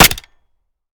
Home gmod sound weapons papa320
weap_papa320_fire_last_plr_mech_03.ogg